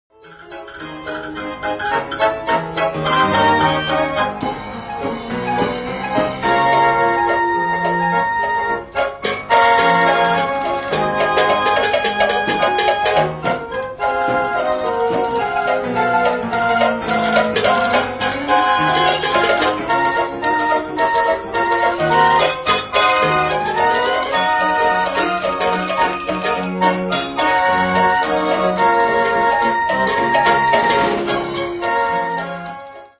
Check out the temple block and cow-bell combination.